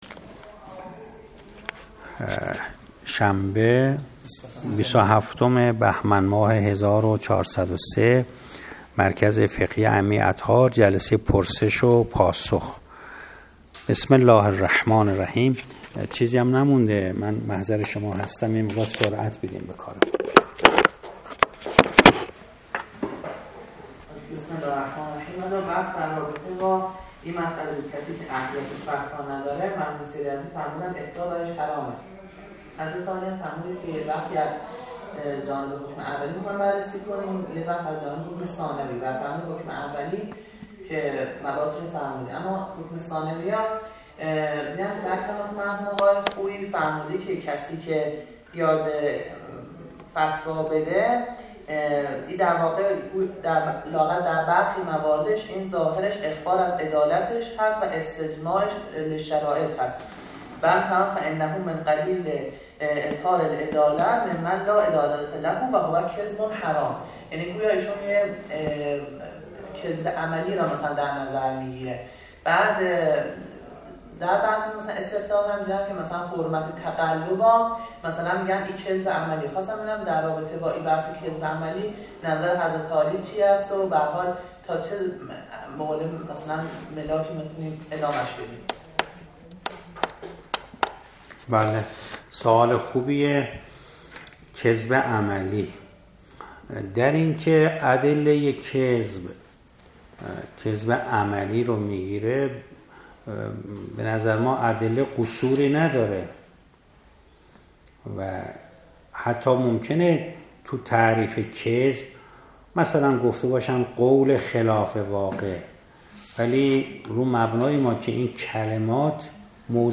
درس خارج فقه و اصول فقه تا جلسه 76 اصول تا جلسه 62